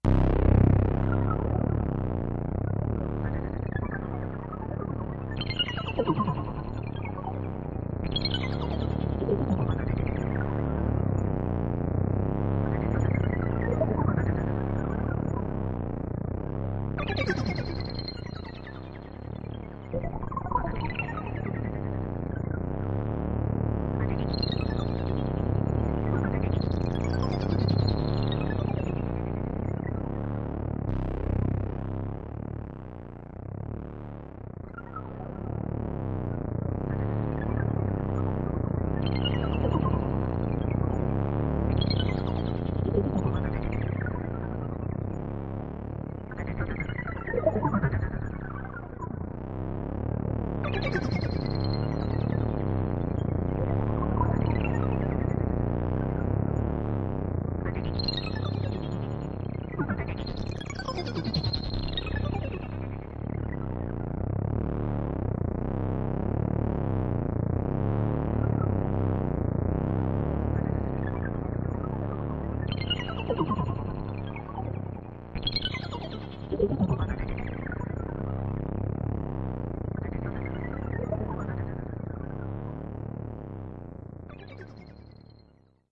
科幻无人机II (有哔哔声和啵啵声)
描述：科幻小说 嗡嗡声和嘘声嗡嗡作响。在合成器上创建。
Tag: 实验室 合成器 原子 FX 空间 科幻